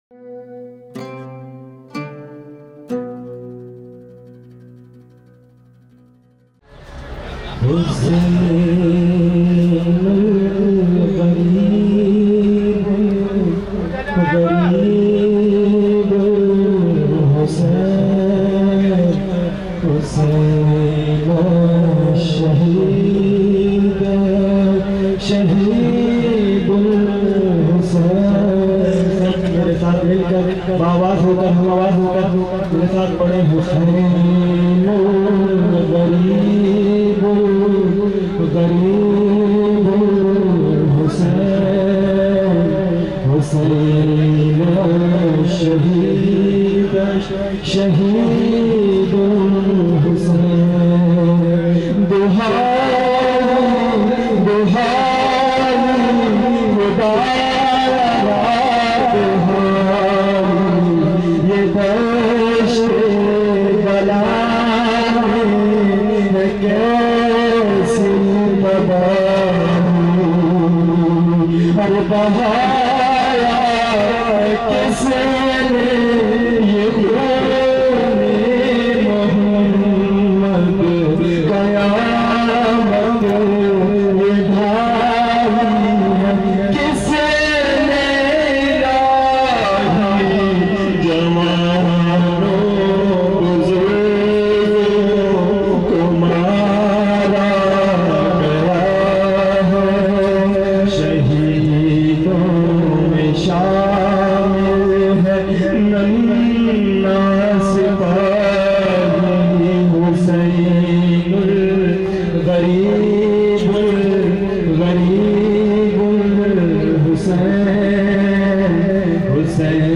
مداحی
اجتماع اردو زبان‌ها | عمود 270 - اربعین 1397